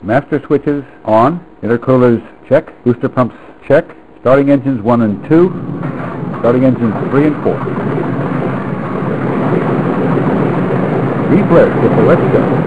snd_21302_Takeoff.wav